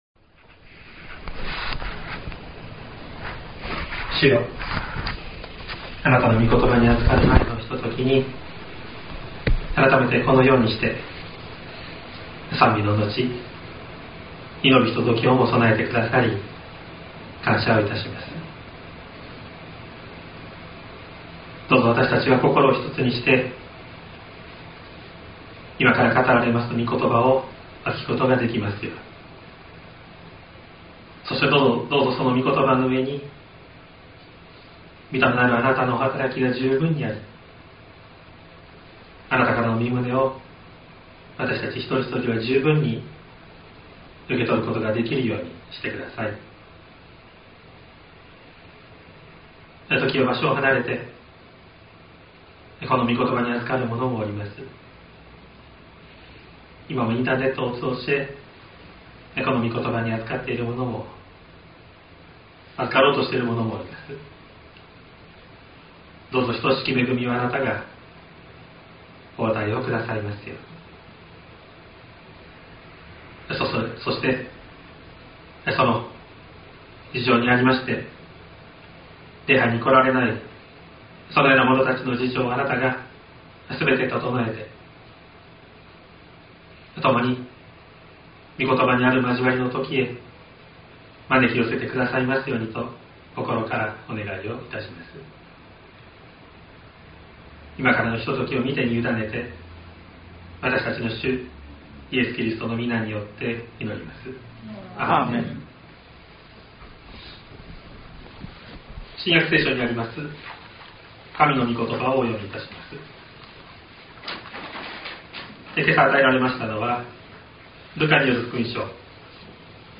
2025年01月12日朝の礼拝「宴席の目的」西谷教会
説教アーカイブ。
音声ファイル 礼拝説教を録音した音声ファイルを公開しています。